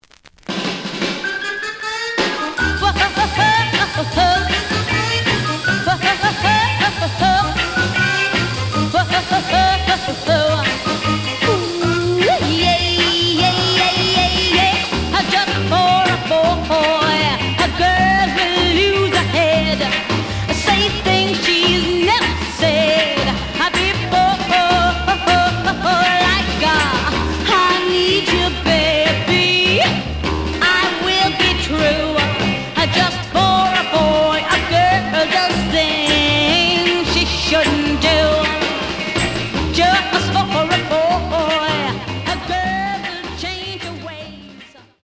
試聴は実際のレコードから録音しています。
●Genre: Rhythm And Blues / Rock 'n' Roll
●Record Grading: G+ (両面のラベルにダメージ。盤に若干の歪み。傷は多いが、プレイはまずまず。)
G+, G → 非常に悪い。ノイズが多い。